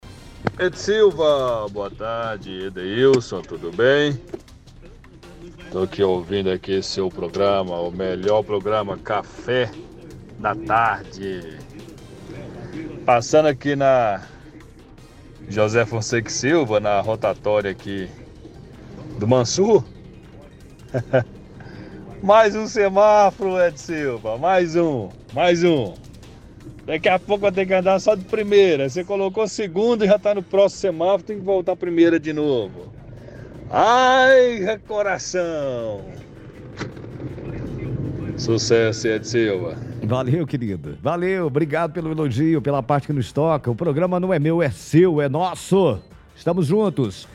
– Ouvinte reclama de instalação de semáforo na av. José Fonseca e Silva.